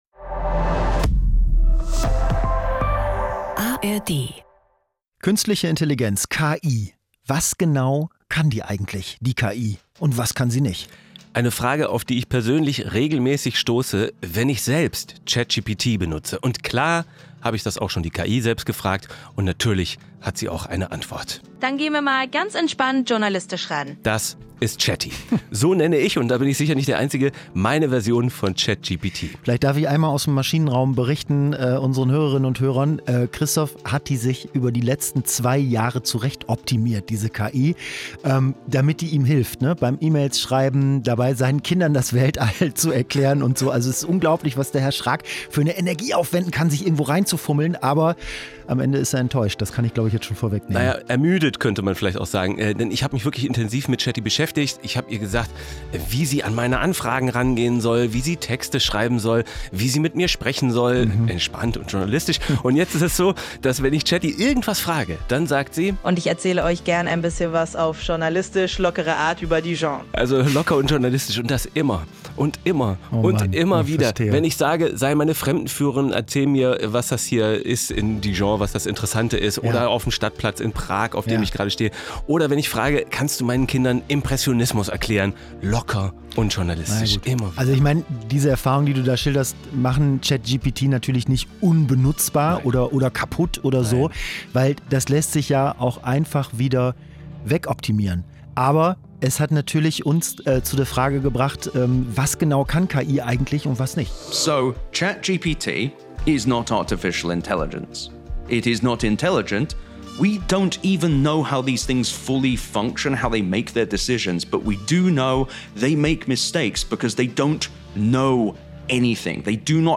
Zwei Freunde, zwei Meinungen, ein News-Podcast